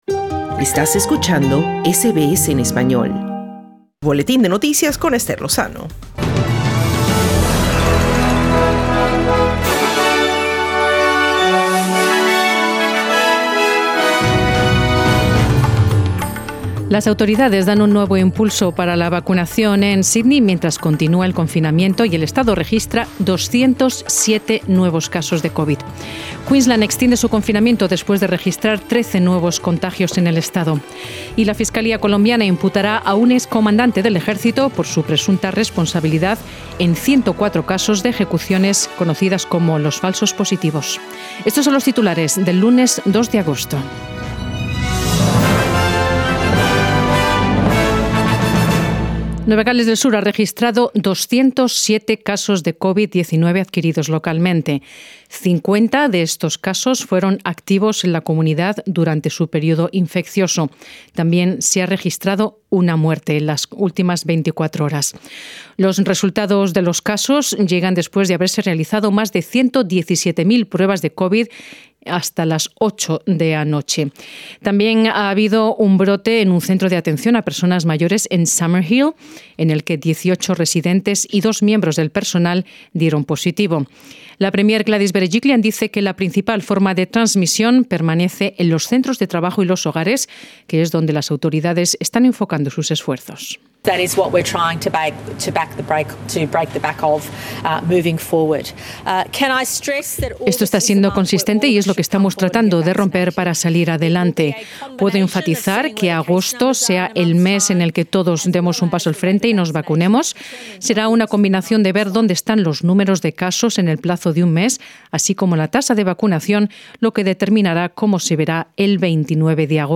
Noticias SBS Spanish | 2 agosto 2021